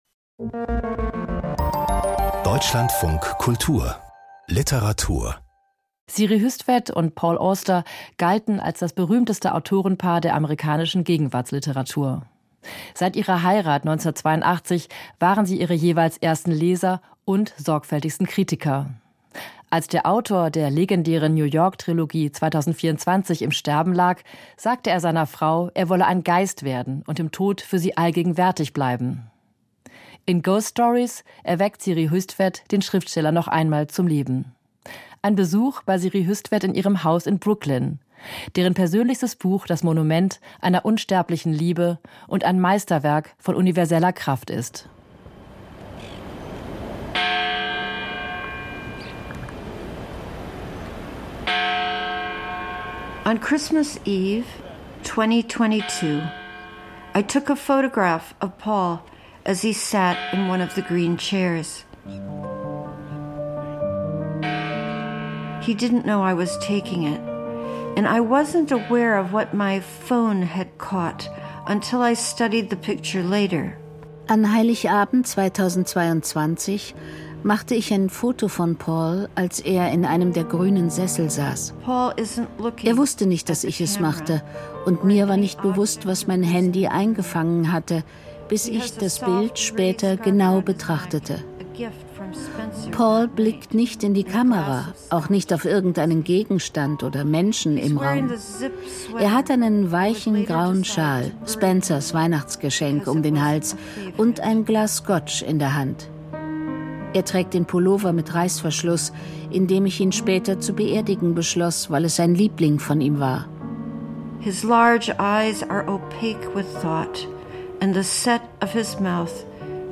Mit ihrem neuen Buch setzt Siri Hustvedt ihrem 2024 verstorbenen Ehemann Paul Auster ein Denkmal. Ein Besuch bei der US-Autorin in ihrem Haus in Brooklyn.
Literarische Features und Diskussionen.